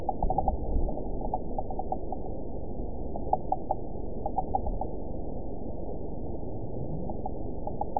event 912136 date 03/19/22 time 01:36:06 GMT (3 years, 1 month ago) score 9.45 location TSS-AB05 detected by nrw target species NRW annotations +NRW Spectrogram: Frequency (kHz) vs. Time (s) audio not available .wav